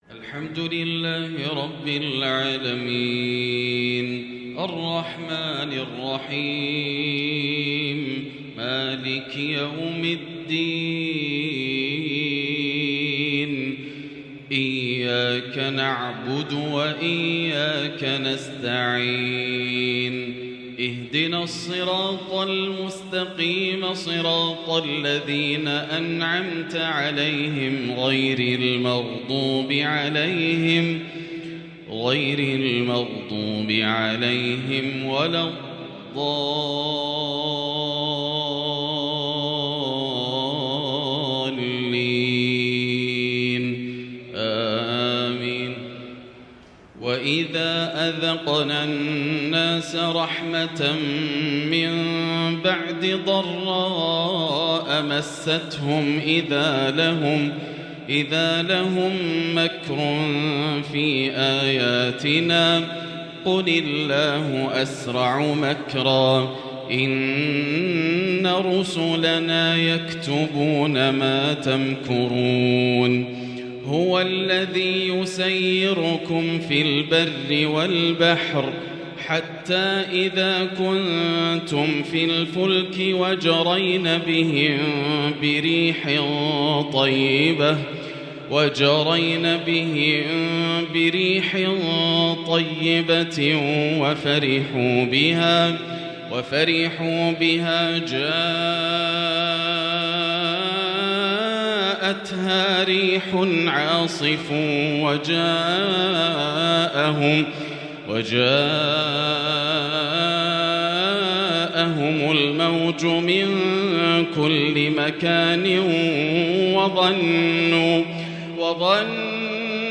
تحبير مذهل وتغني لا يوصف من الآسر د.ياسر الدوسري في تلاوات لاتُنسى من المسجد الحرام > مقتطفات من روائع التلاوات > مزامير الفرقان > المزيد - تلاوات الحرمين